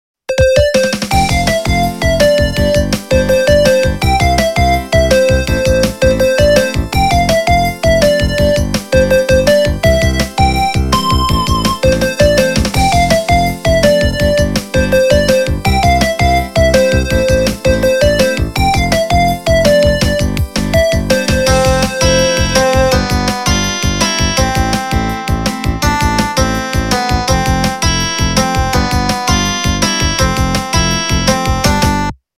- русская эстрада
качество понижено и присутствуют гудки
Загрузите, прослушайте и сохраните полифоническую мелодию.